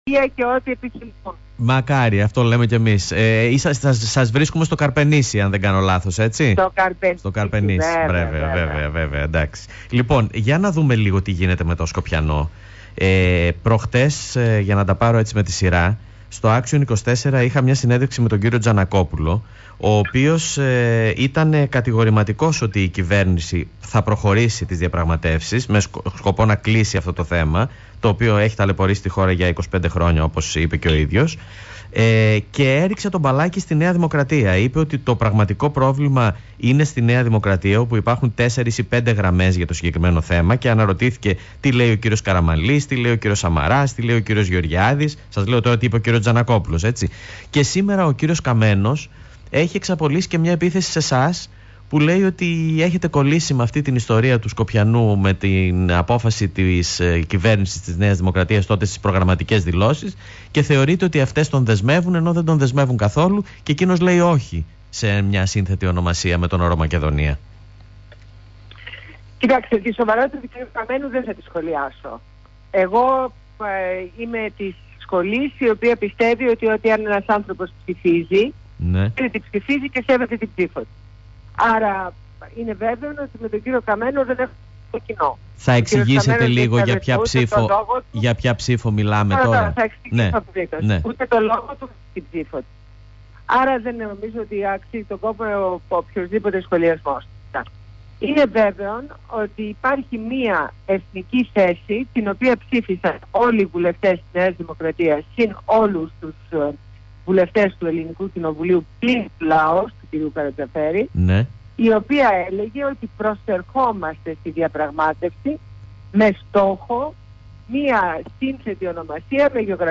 Συνέντευξη στο Θέμα radio